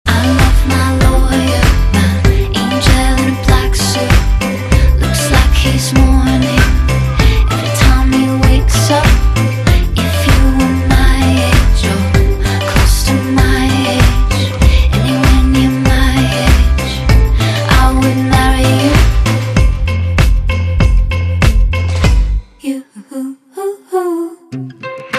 欧美歌曲